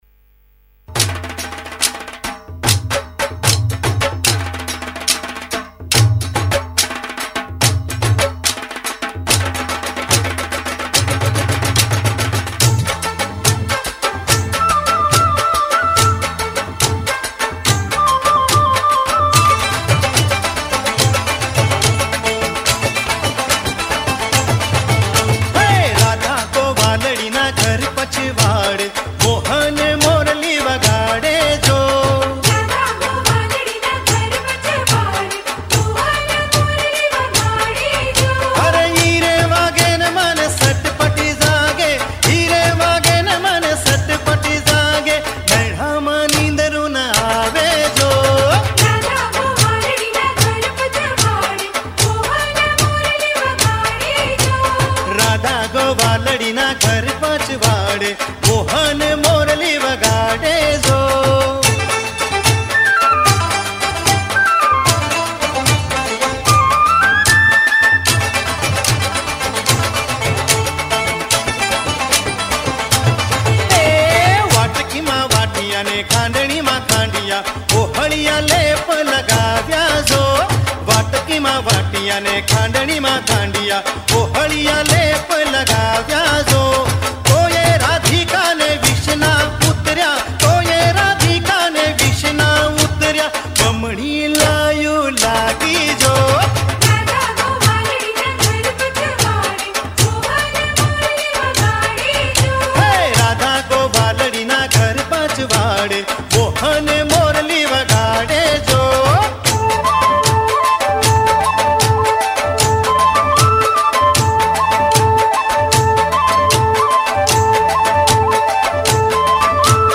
Gujarati Songs And Dandiya